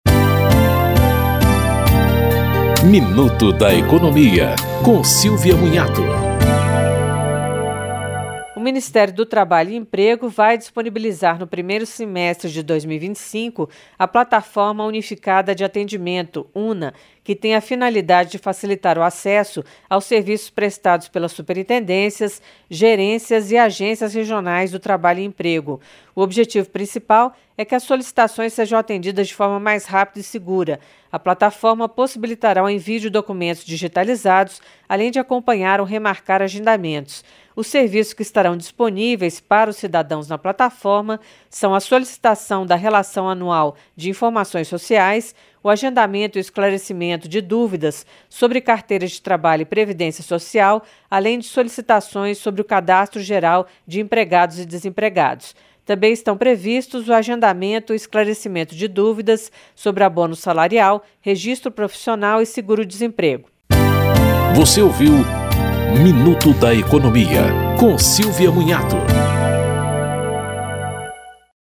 Apresentação